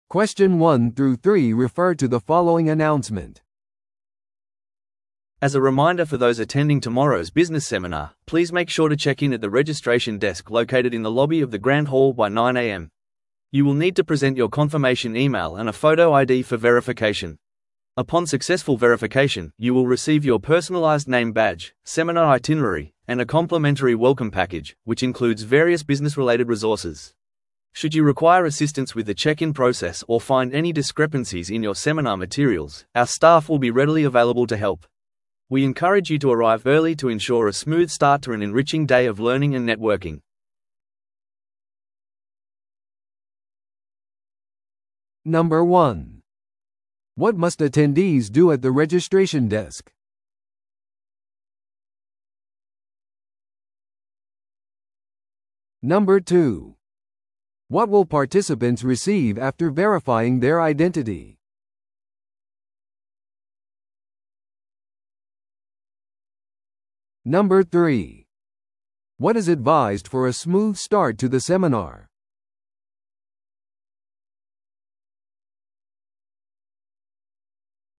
TOEICⓇ対策 Part 4｜ビジネスセミナーの受付案内 – 音声付き No.095